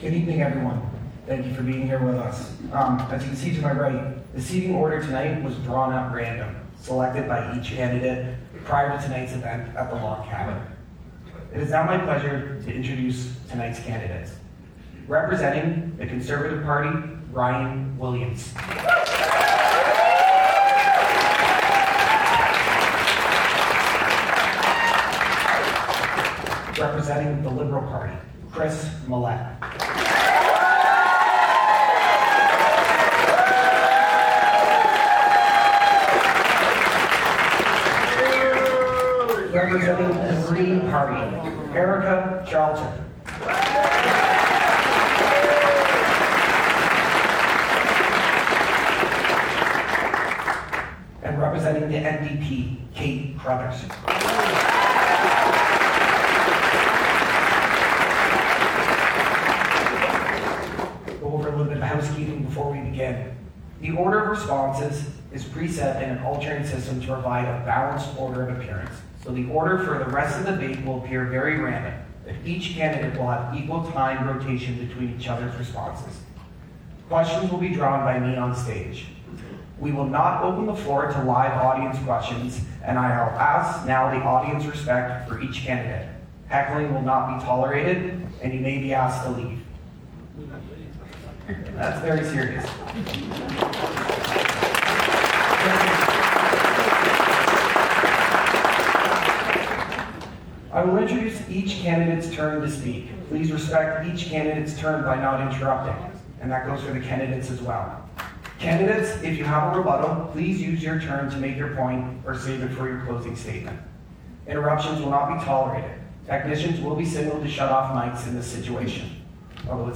Federal Bay of Quinte candidates debate at Empire Theatre
This time, it was the federal candidates that took part.
The debate was formatted as follows: Each candidate presented an opening statement for three minutes.
A strong turnout cheered, booed and in some cases laughed at answers provided by each of the candidates. A number of topics were broached including housing & affordability, trade and strengthening the relationship with the US, information, transparency and media literacy, and homelessness & social supports among others.